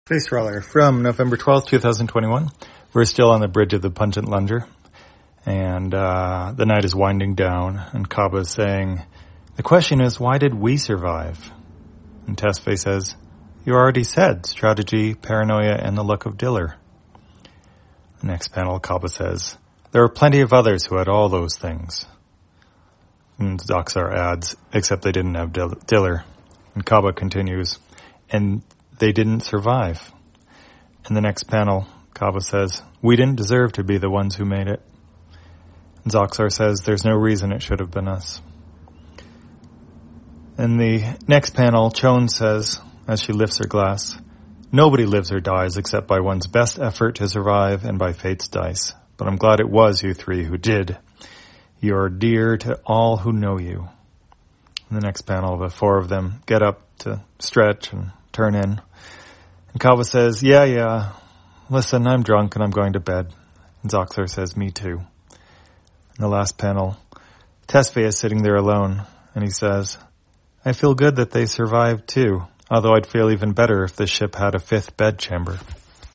Spacetrawler, audio version For the blind or visually impaired, November 12, 2021.